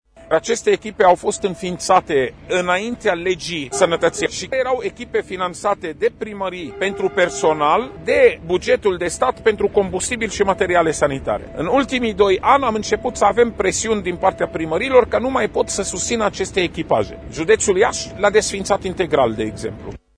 Șeful Departamentului pentru Situații de Urgență, dr. Raed Arafat a declarat astăzi, la Tîrgu Mureș, că desființarea serviciilor SMURD civile ar duce la pierderea de vieți.